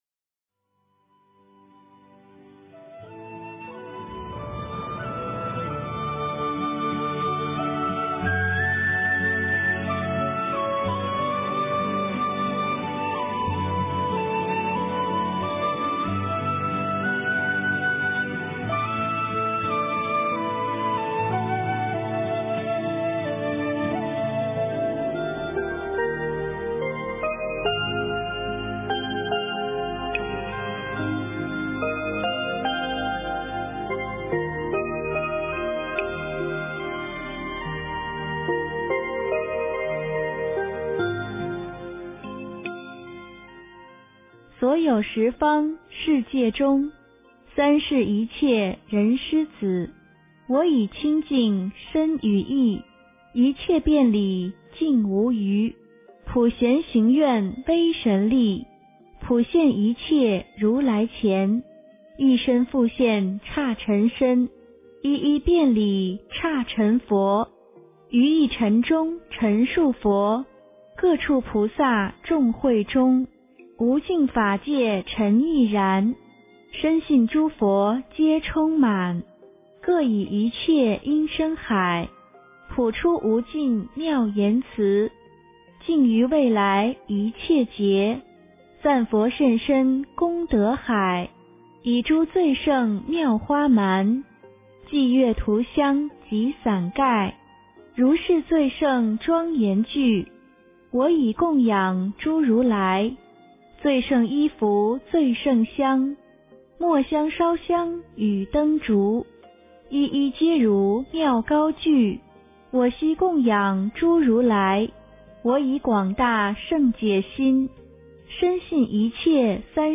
佛音 诵经 佛教音乐 返回列表 上一篇： 金刚经第十五至第十七部 下一篇： 六字真言颂 相关文章 皈依佛陀-梵语--佚名 皈依佛陀-梵语--佚名...